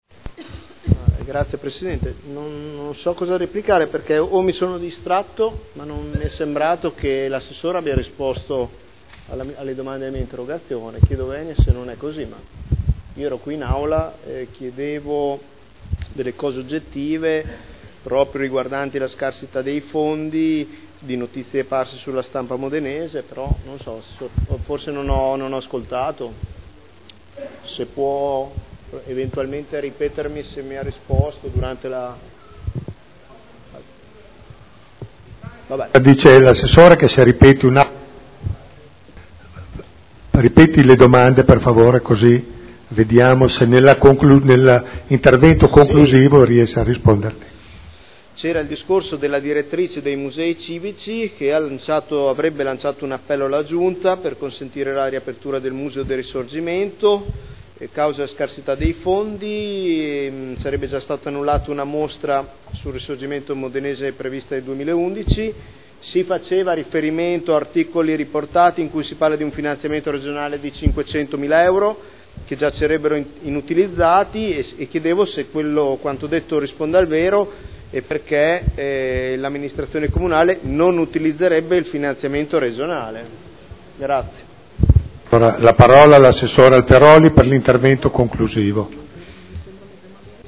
Seduta del 28/02/2011.
Audio Consiglio Comunale